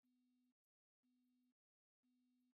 Τεστ ακοογράμματος
audiocheck.net_puretone_250_0.mp3